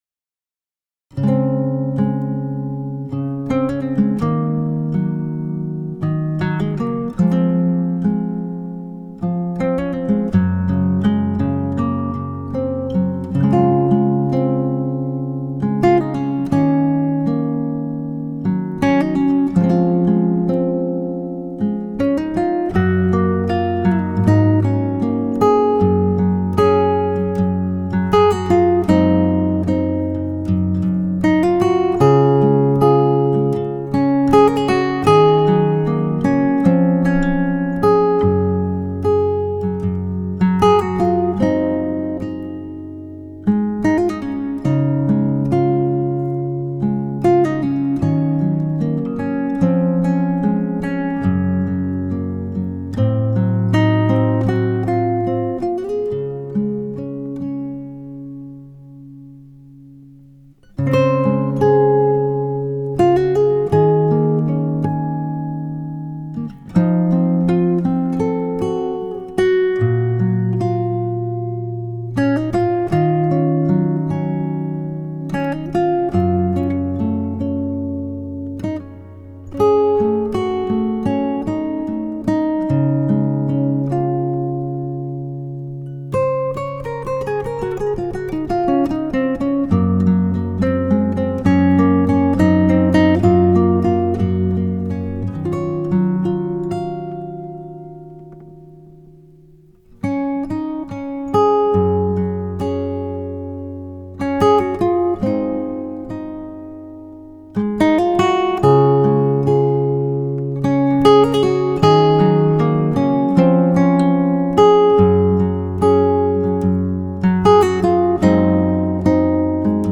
سبک آرامش بخش , پیانو , عصر جدید , موسیقی بی کلام